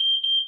mine_trigger.ogg